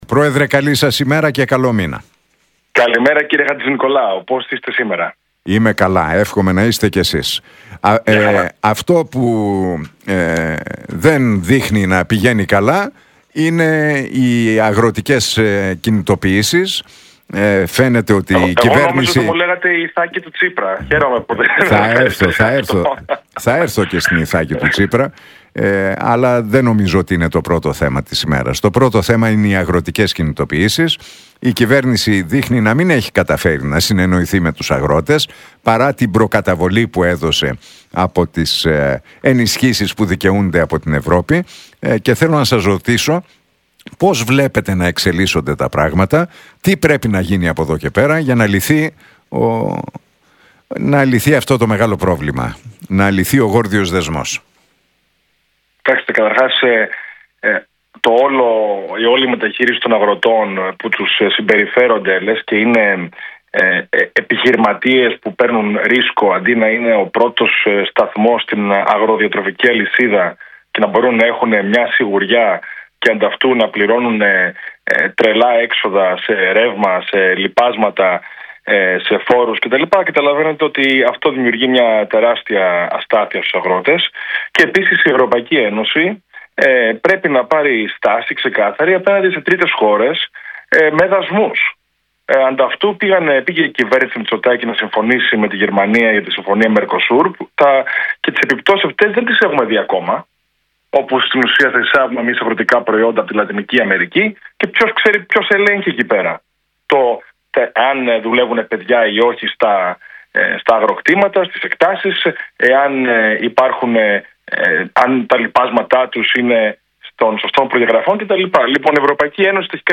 Για τις αγροτικές κινητοποιήσεις, τον πρωτογενή τομέα και τις εξελίξεις στην Κεντροαριστερά μίλησε ο πρόεδρος του Κινήματος Δημοκρατίας, Στέφανος Κασσελάκης στον Νίκο Χατζηνικολάου από τη συχνότητα του Realfm 97,8.